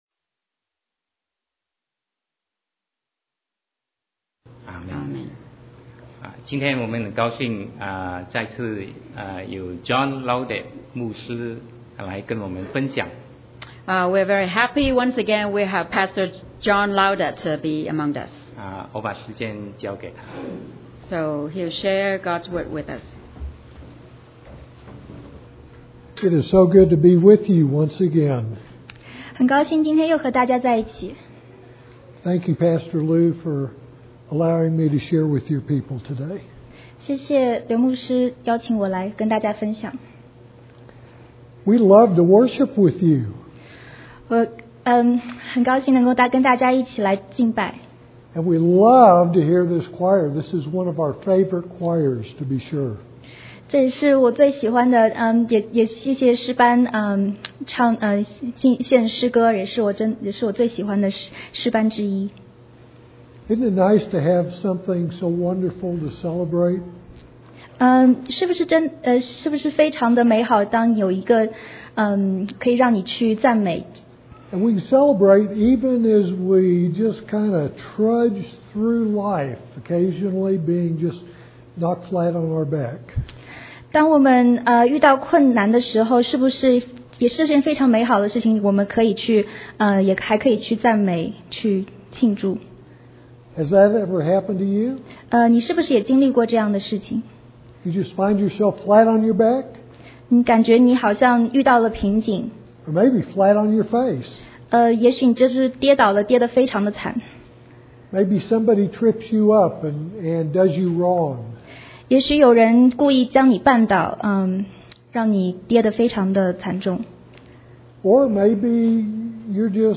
Sermon 2016-08-28 When We Fall
Sermon audio